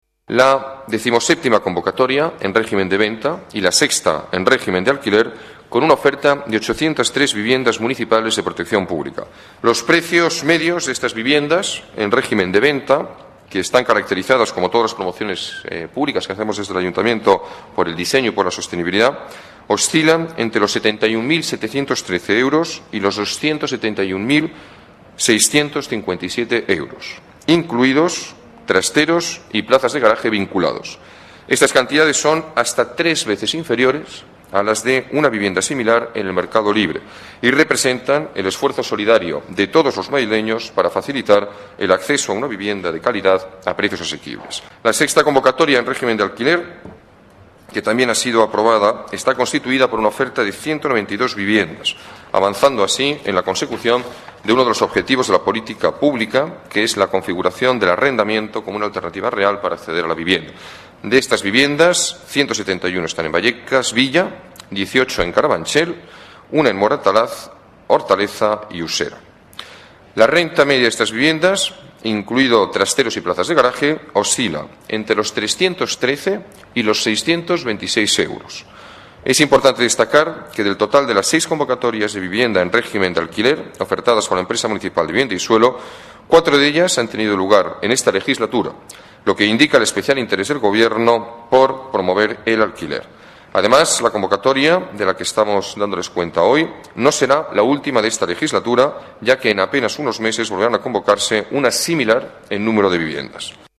Nueva ventana:Declaraciones del alcalde